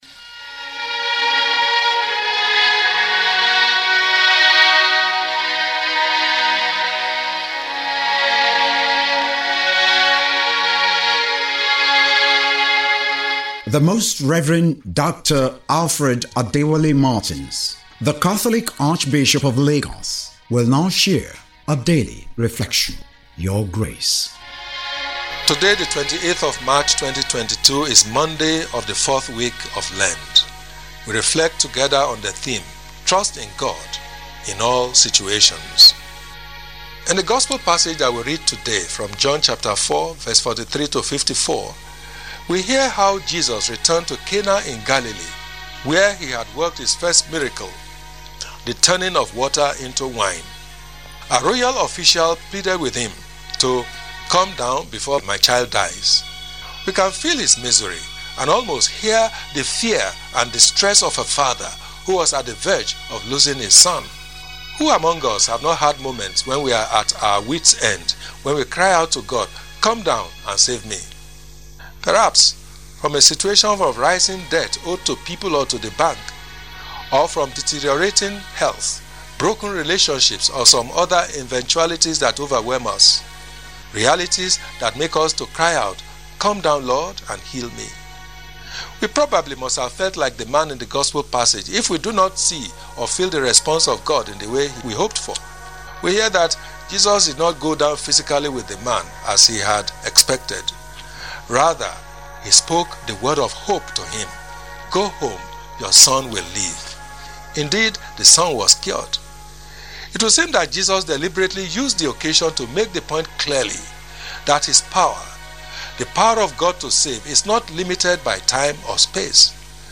LENTEN REFLECTION WITH ARCHBISHOP MARTINS.
MONDAY-28-LENTEN-TALK.mp3